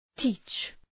Προφορά
{ti:tʃ}